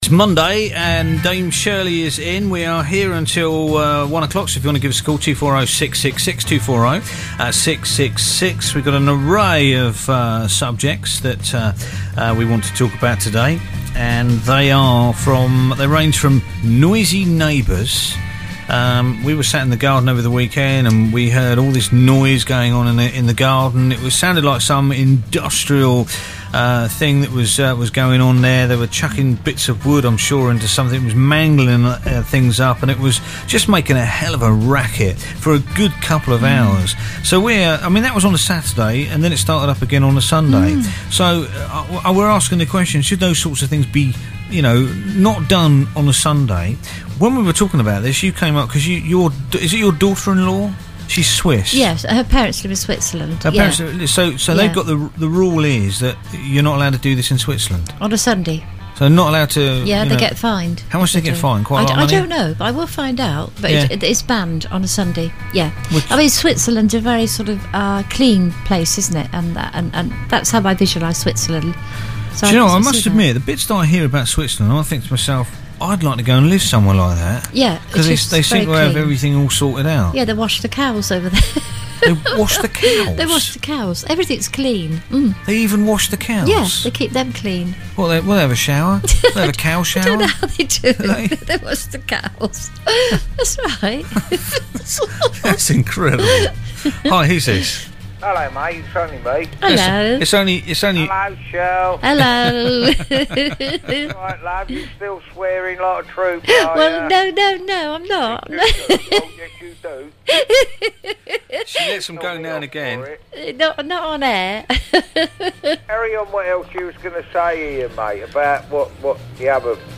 This Weeks Monday Phone In